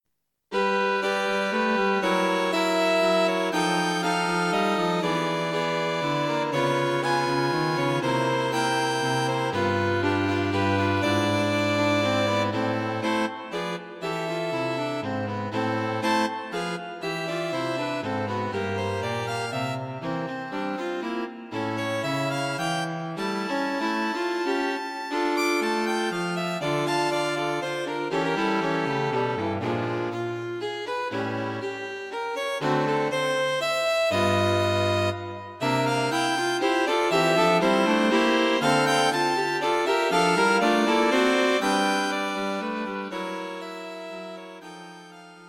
String Quartet A bright, modern, light piece in waltz time.
Combining rhythms with interesting harmonies.